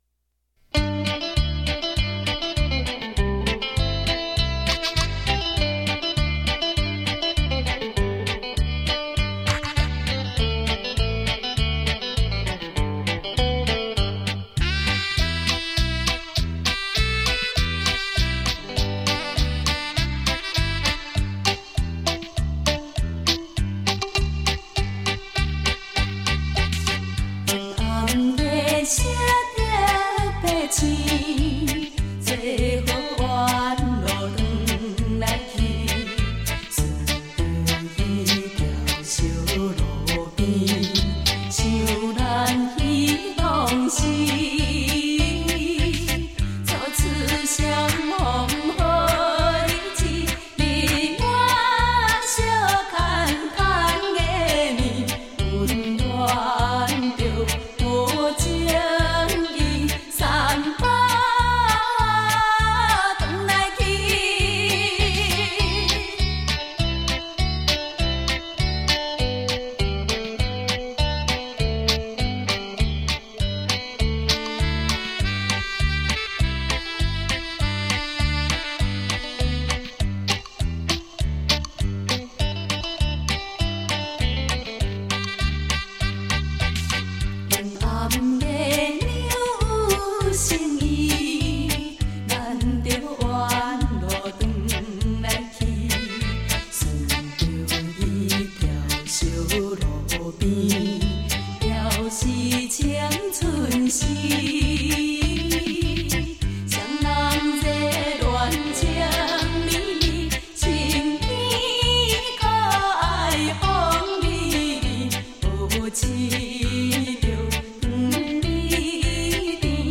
台语二重唱